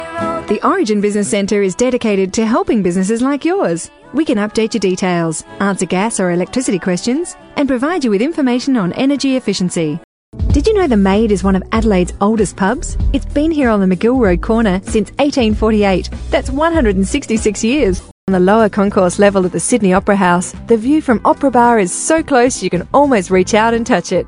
Female
English (Australian)
I'm described as natural and friendly and booked by studios looking for young & perky, a mum, energetic, smooth or seductive.
Phone Greetings / On Hold
0724On_Hold.mp3